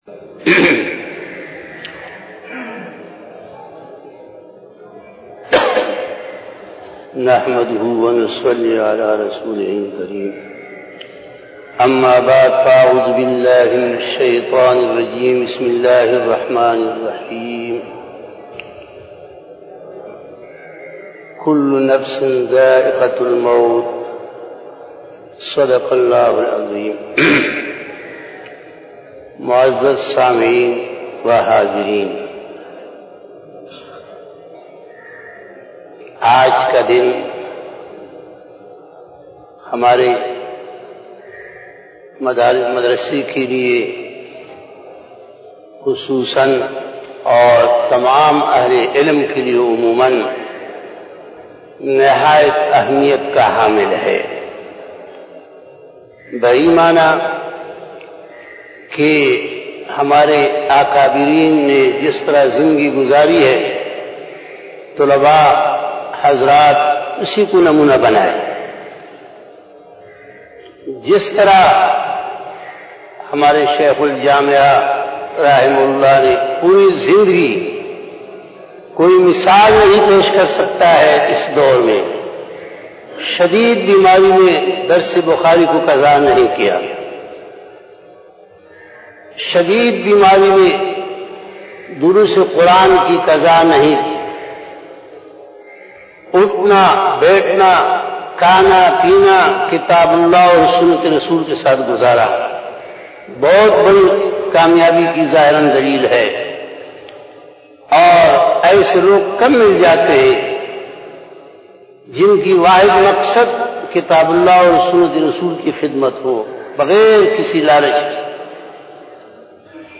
Taaziayati Bayana